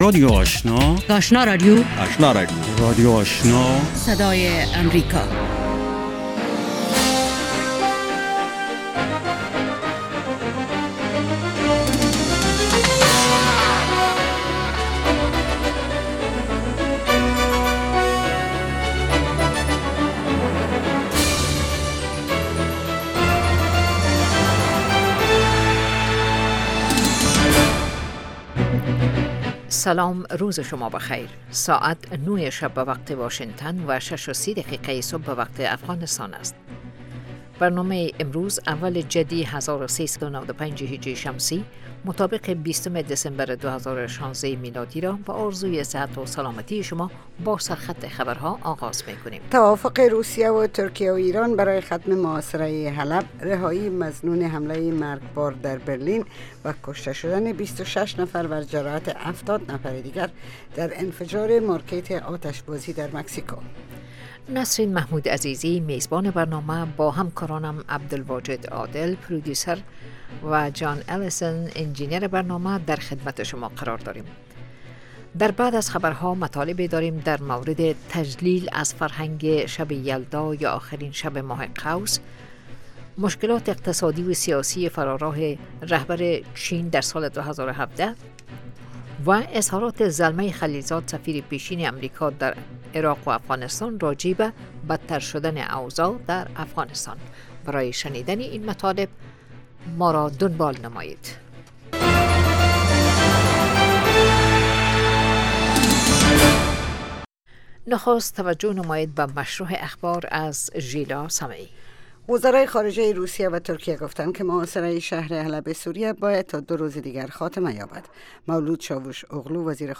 دومین برنامه خبری صبح
دومین برنامه خبری صبح، حاوى تازه ترين خبرهاى افغانستان و جهان است. این برنامه، همچنین شامل گزارش هایی از افغانستان، ایالات متحده امریکا و مطلب مهمی از جهان می باشد. پیش گویی وضع هوای افغانستان و چند رویداد ورزشی از جهان نیز شامل این برنامه است.